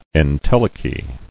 [en·tel·e·chy]